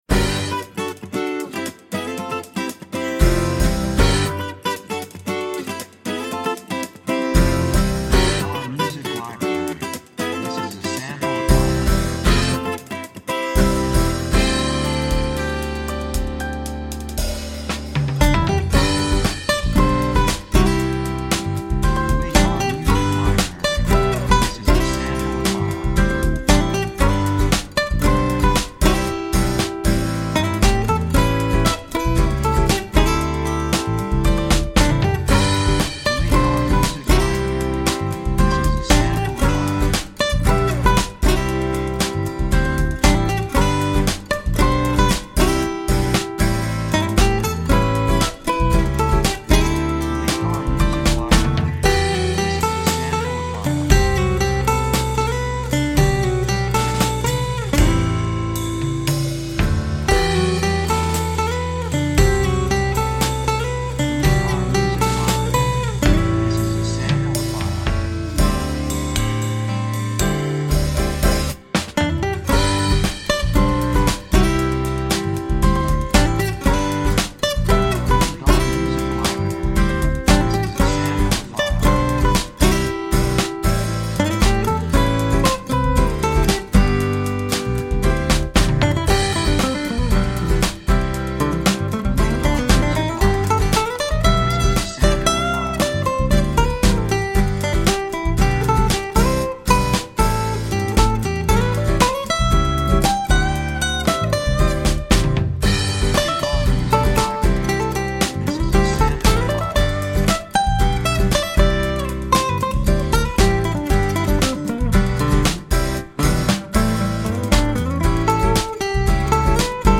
3:05 116 プロモ, アコースティック
雰囲気幸せ, 穏やか, 高揚感, 感情的
楽器アコースティックギター, ピアノ
サブジャンルフォークポップ
テンポやや速い